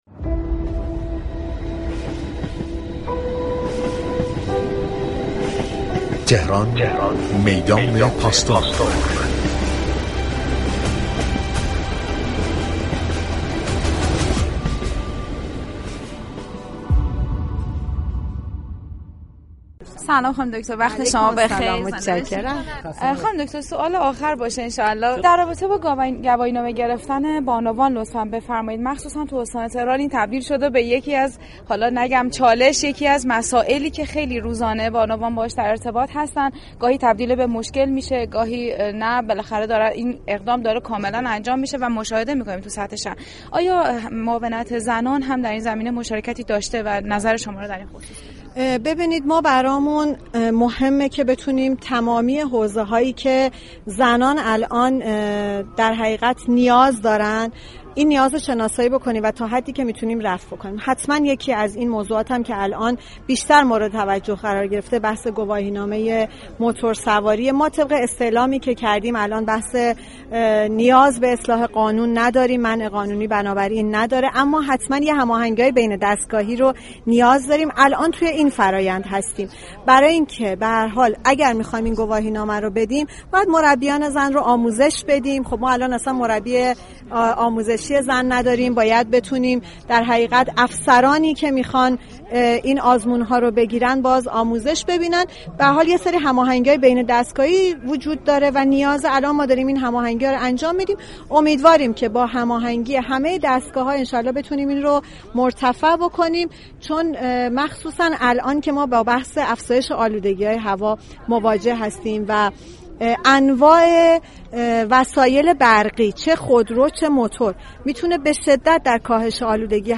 زهرا بهروزآذر معاون رییس جمهور در امور زنان و خانواده در گفتگوی اختصاصی با خبرنگار رادیو تهران، با اشاره به ضرورت شناسایی نیازهای بانوان گفت: صدور گواهینامه موتورسواری برای زنان از جمله این موارد است كه طبق استعلام‌های انجام شده، صدور آن برای بانوان منع قانونی ندارد اما نیازمند هماهنگی بین دستگاهی است كه درحال انجام این فرآیند هستیم.